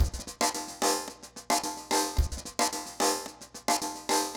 RemixedDrums_110BPM_37.wav